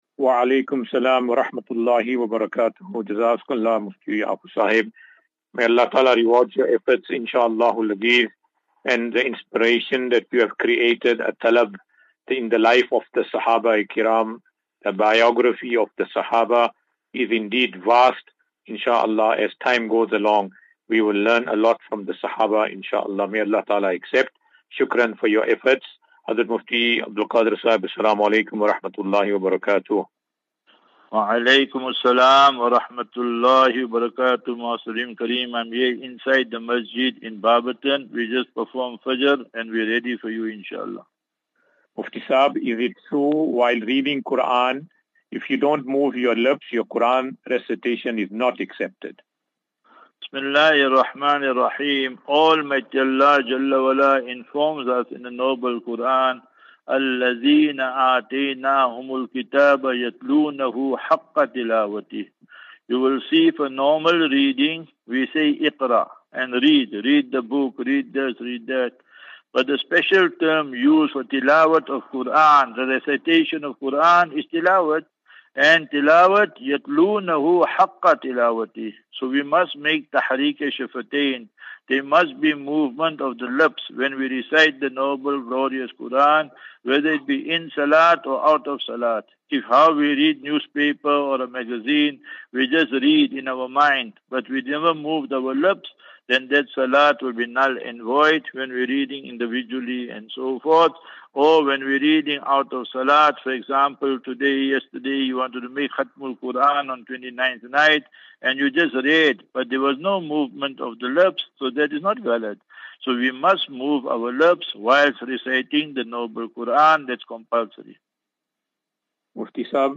As Safinatu Ilal Jannah Naseeha and Q and A 9 Apr 09 April 2024.